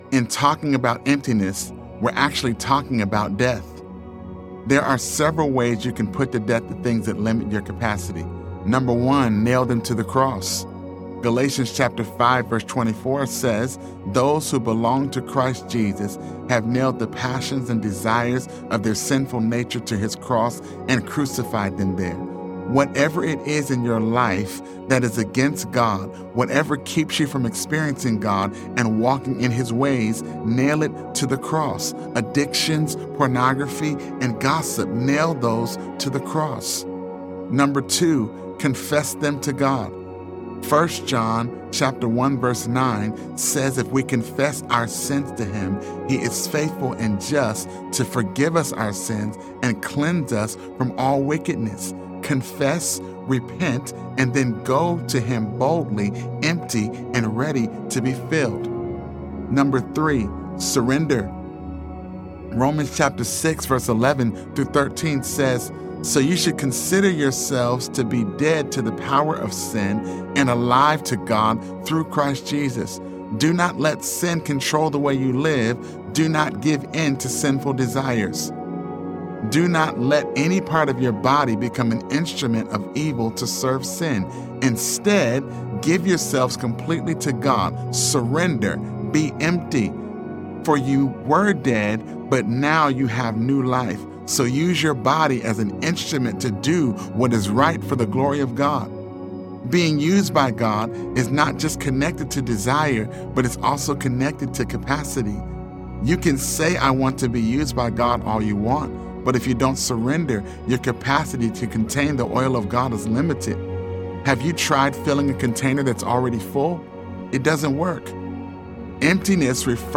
It’s Happening Audiobook